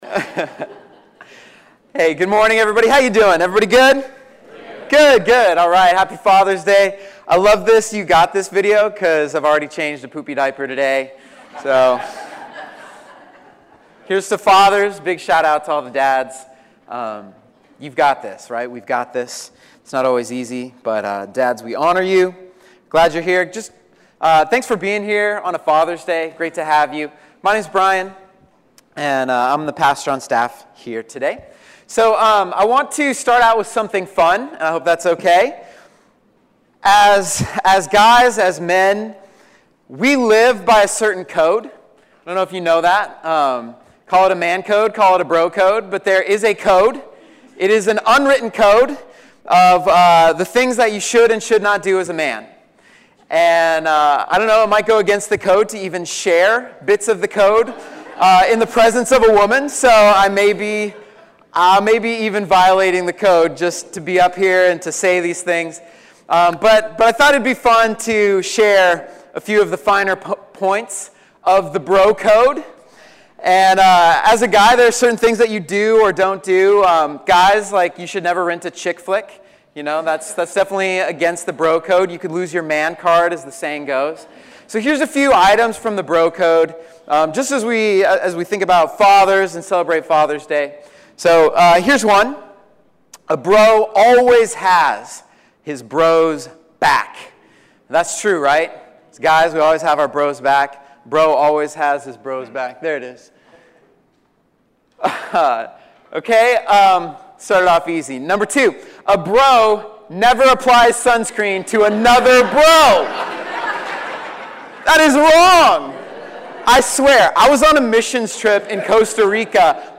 Father's Day message 2017